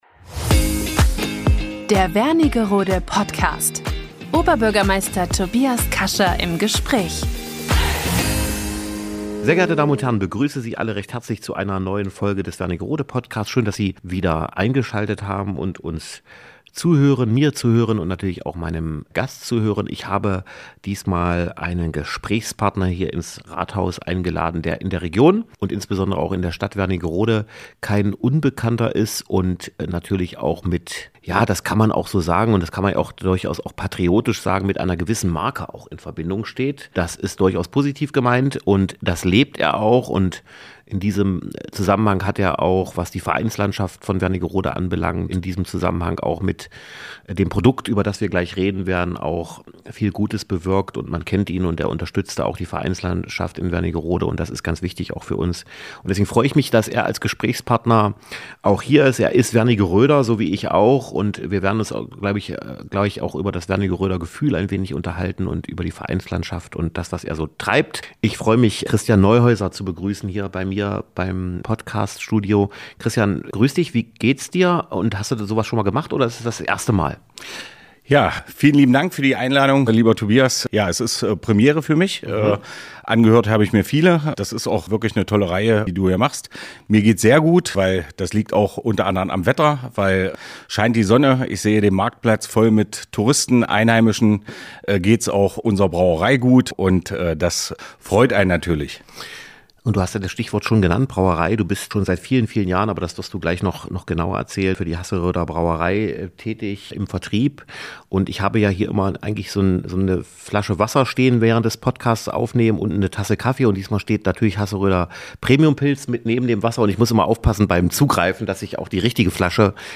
Wernigerode Podcast #44 - Oberbürgermeister Tobias Kascha im Gespräch